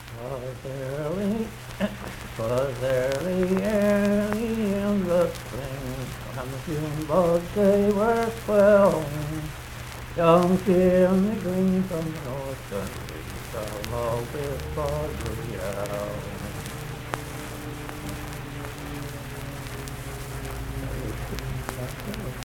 Unaccompanied vocal music
Voice (sung)
Marion County (W. Va.), Mannington (W. Va.)